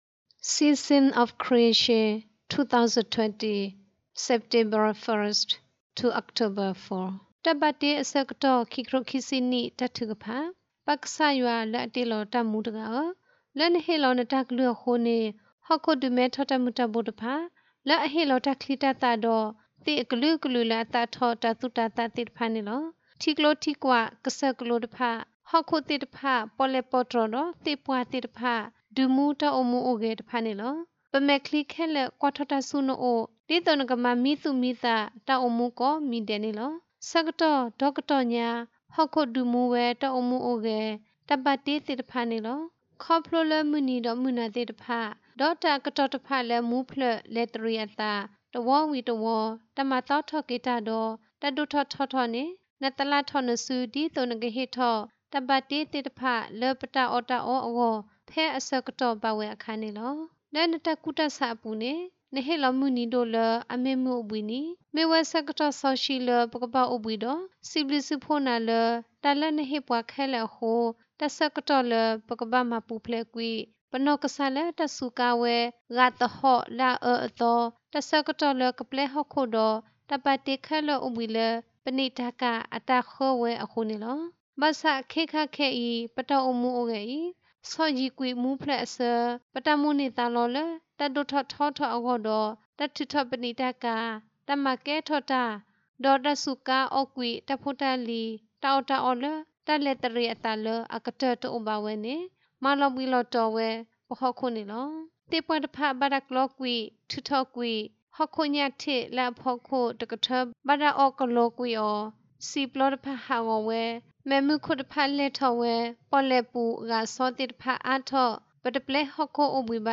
season_creation_prayer.mp3